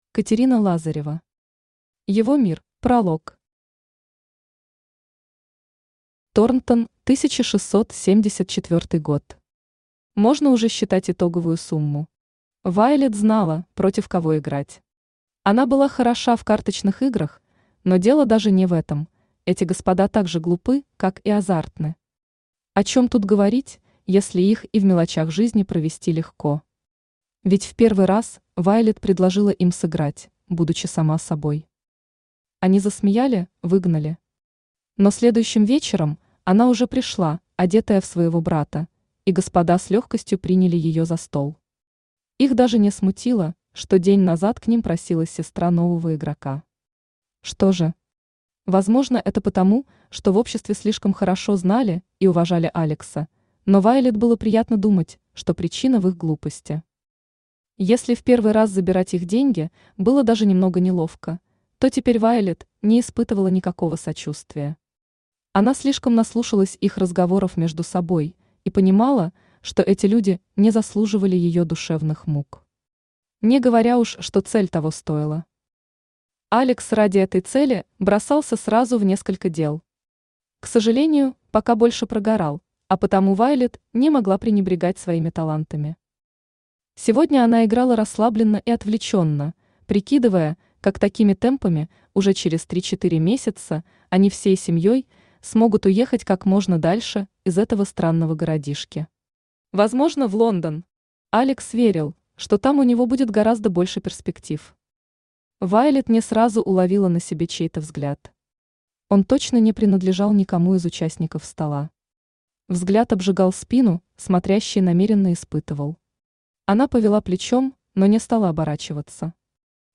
Aудиокнига Его мир Автор Катерина Лазарева Читает аудиокнигу Авточтец ЛитРес. Прослушать и бесплатно скачать фрагмент аудиокниги